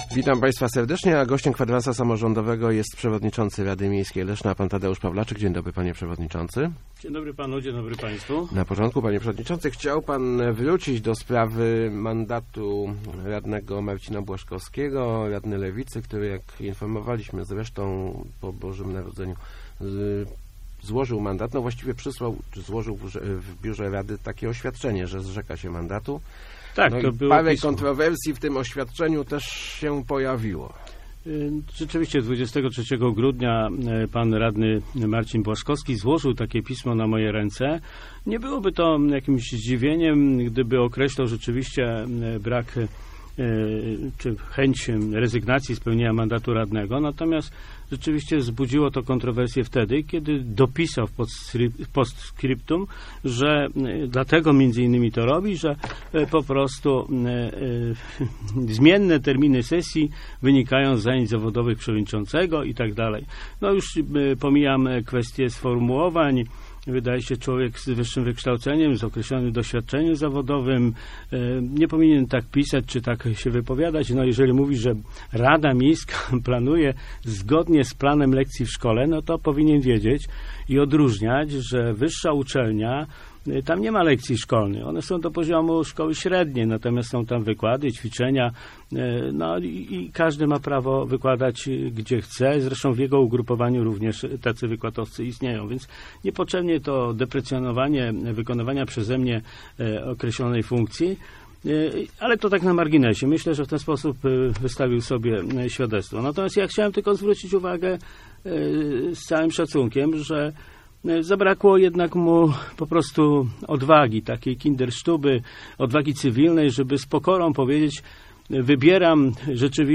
Gościem Kwadransa był Tadeusz Pawlaczyk, przewodniczący Rady Miejskiej Leszna ...